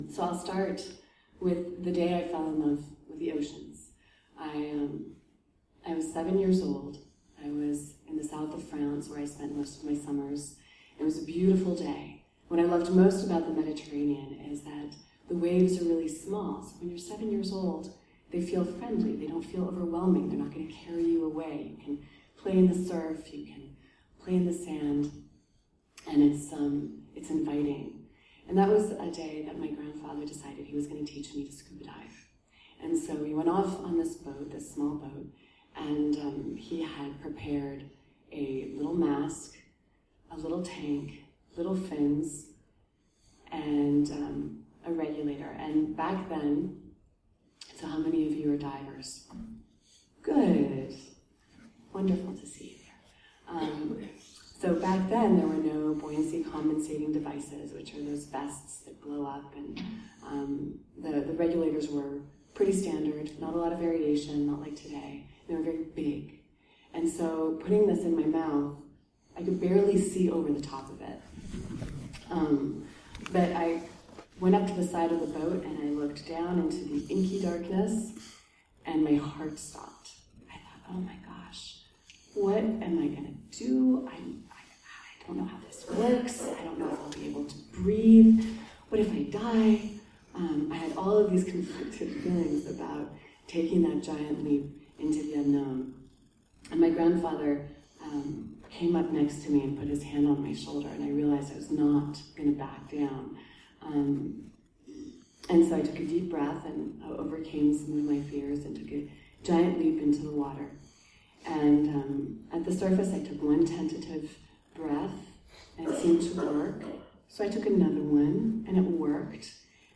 Alexandra Cousteau was in town last night, hosted by the West Virginia Higher Education Policy Commission to kick off the Chancellor’s Speaker Series on STEM (Science, Technology, Engineering and Math) topics.
To the delight of the crowd, made up by mostly students and educators from West Virginia colleges and universities, she said “The most meaningful moments of my life were when I was with a scientist.”
The following are excerpts from her presentation.